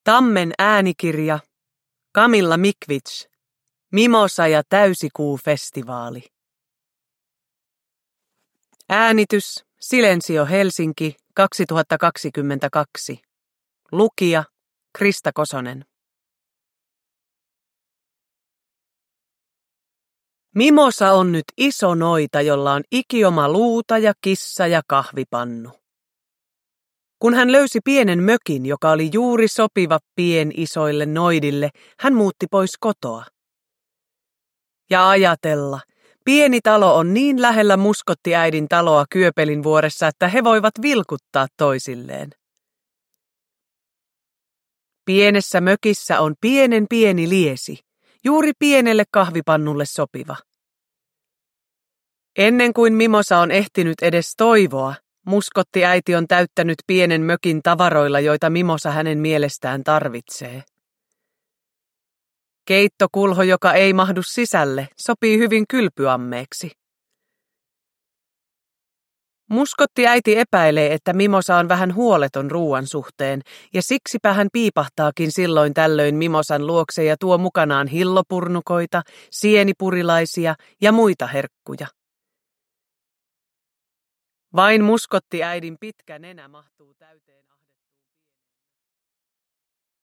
Mimosa ja täysikuufestivaali – Ljudbok – Laddas ner